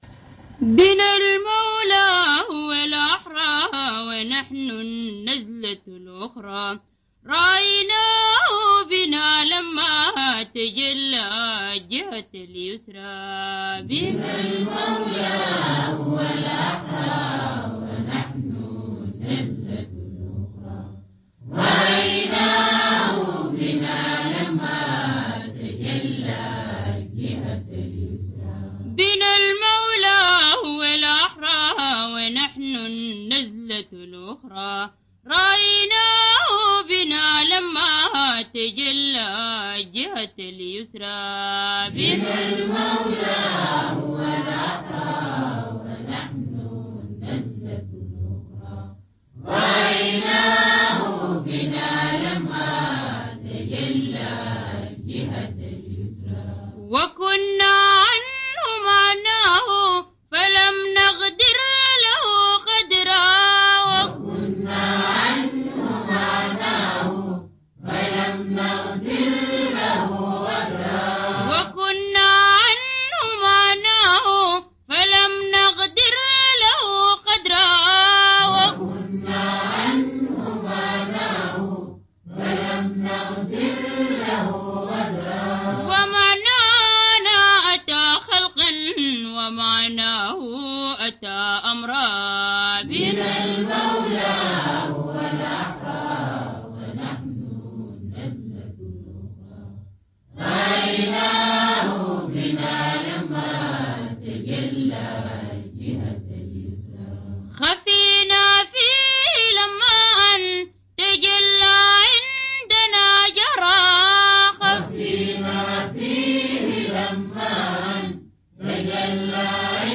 إنشاد